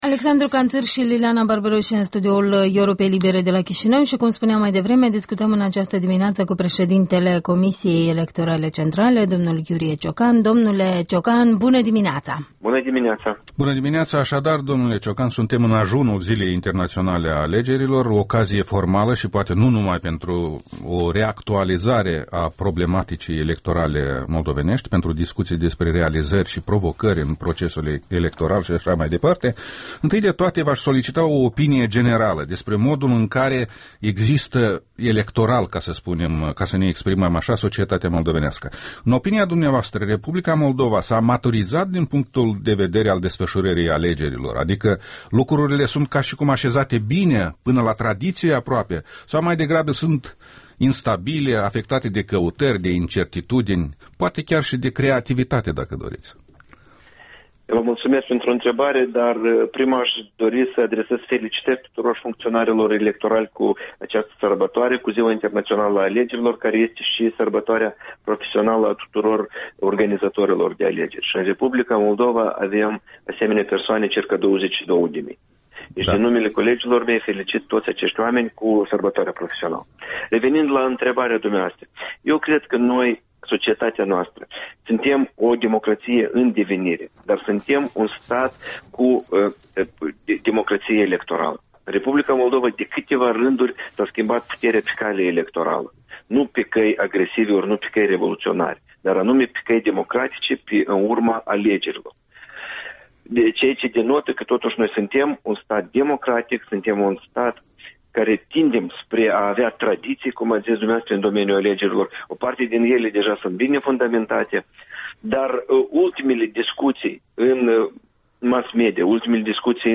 Interviul dimineții cu președintele Comisiei Electorale Centrale de la Chișinău.